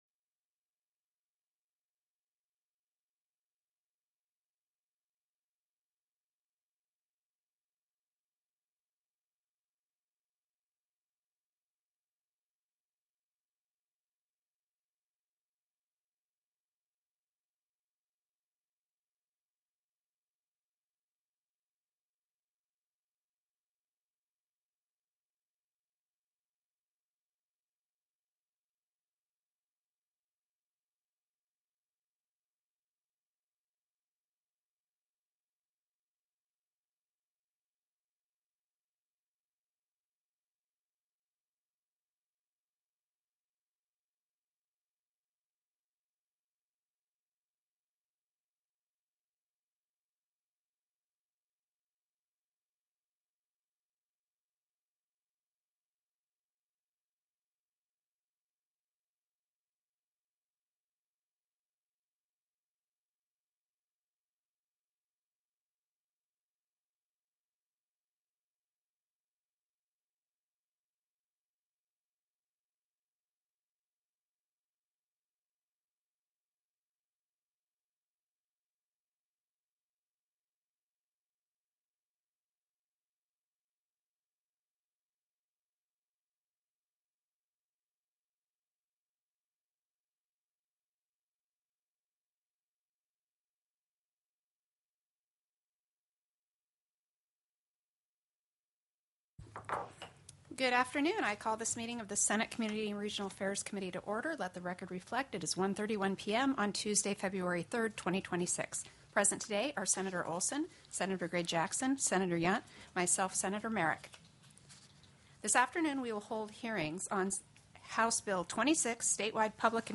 02/03/2026 01:30 PM Senate COMMUNITY & REGIONAL AFFAIRS
The audio recordings are captured by our records offices as the official record of the meeting and will have more accurate timestamps.
+= SB 129 PAYMENT OF CONTRACTS TELECONFERENCED
+= HB 26 STATEWIDE PUBLIC & COMMUNITY TRANSIT PLAN TELECONFERENCED